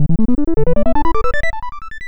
login.wav